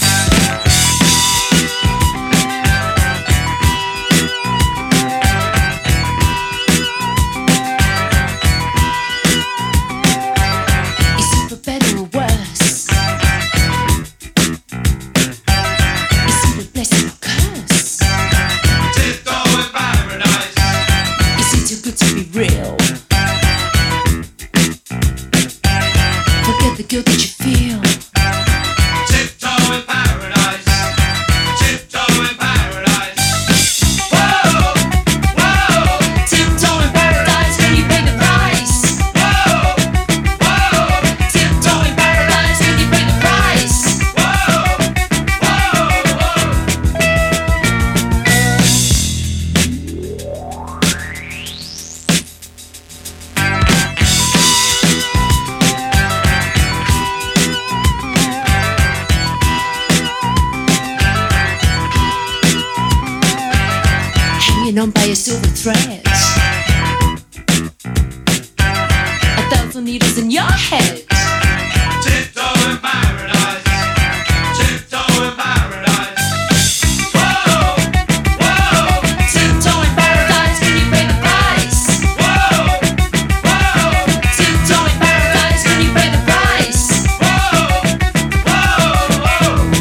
軽やかにスウィングするビートに痛快なギター、謎なサックス・プレイも堪らないフェイク・スウィング・ナンバーです。